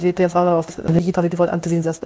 mimic3 audio prompts
de_DE_m-ailabs_angela_merkel.wav